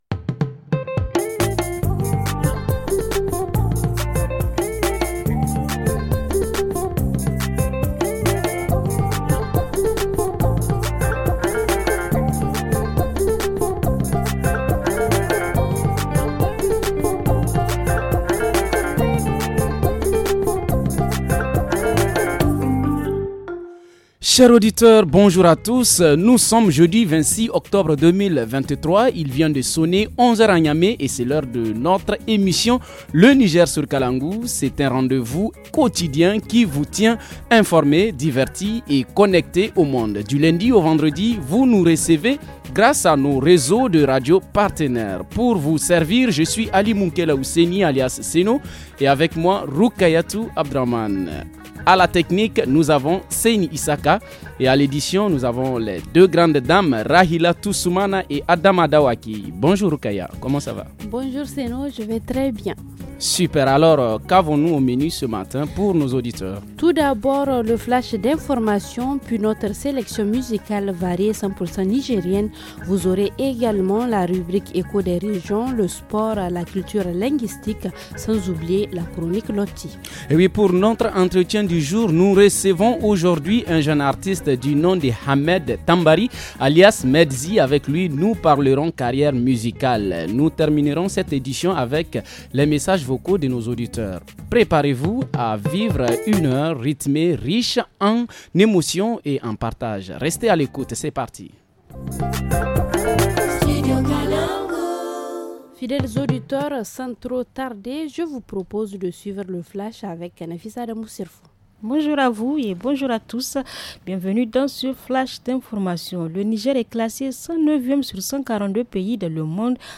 -Culture Linguistique : Explication du « Titre foncier » en langue fululdé ; Playlist musique : FR Le Niger sur kalangou Télécharger l’émission ici.
artiste rappeur